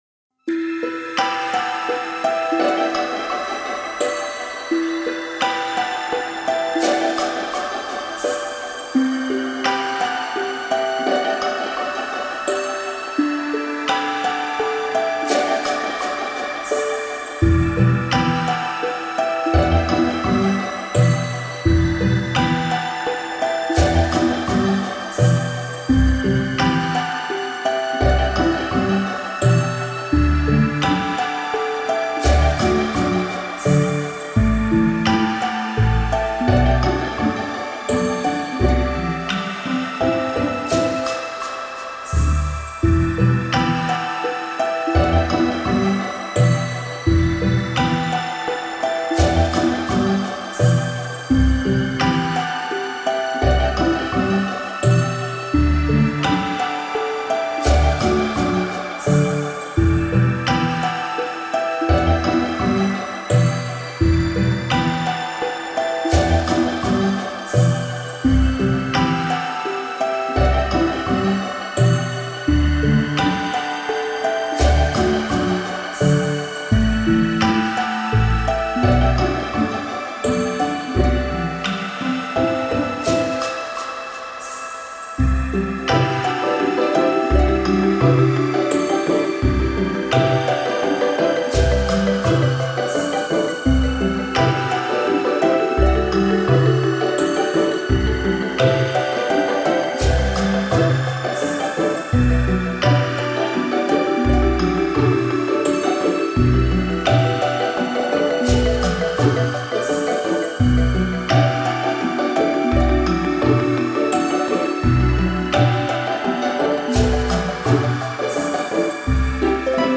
シンセのメロディとハープ、ストリングスのピチカートを使用。 寒さ・寂しさを感じるBGM用アンビエンス系ヒーリング曲。